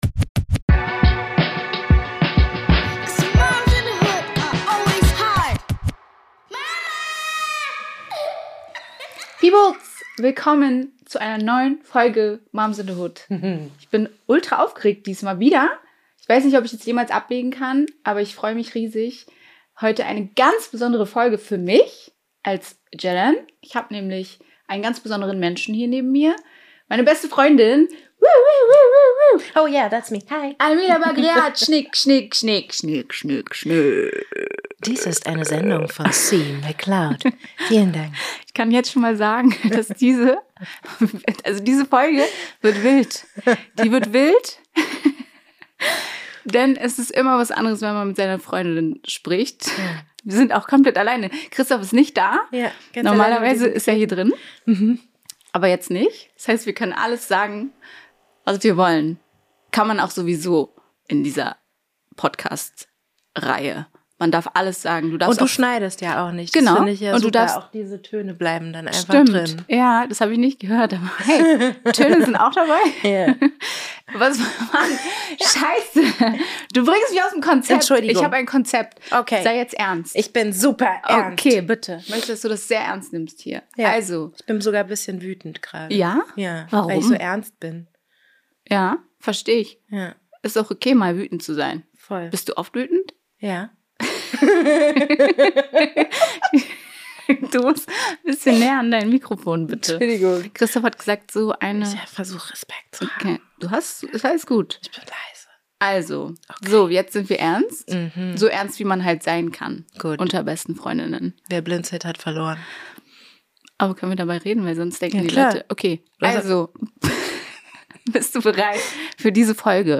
In der 2. Folge, spreche ich mit meiner besten Freundin über unsere zeitgleichen Schwangerschaften, die Geburten und die Zeit danach.